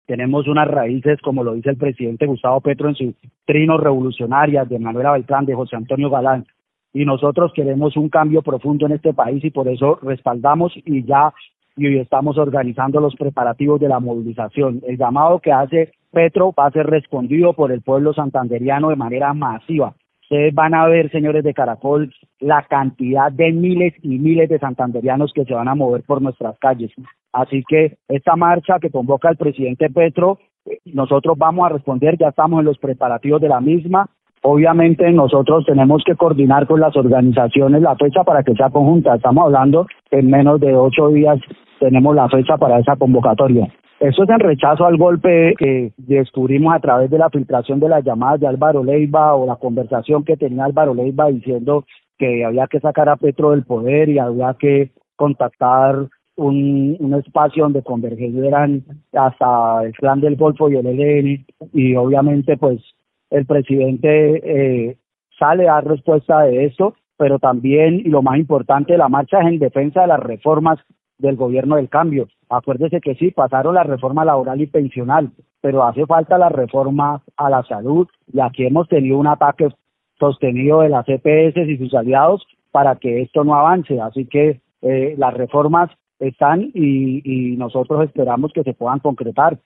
Jorge Flórez, concejal del Pacto Histórico